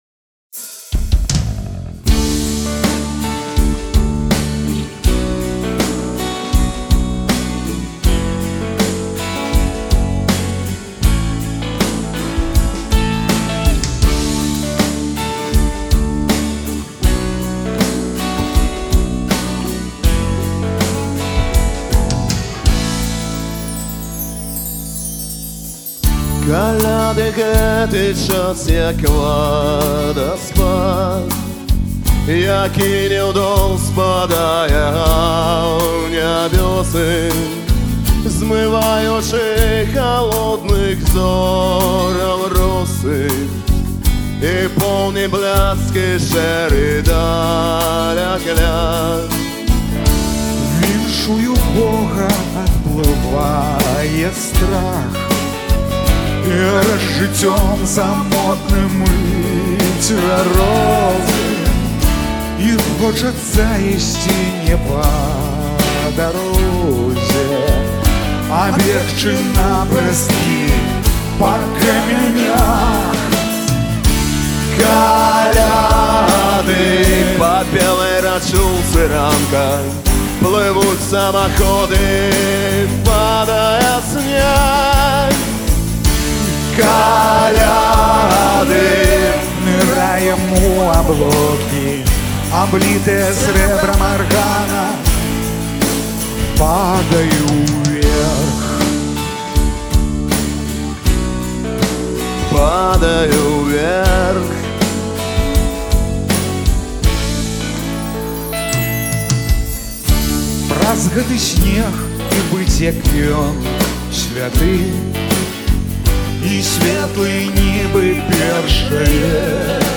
прыгожую калядную кампазыцыю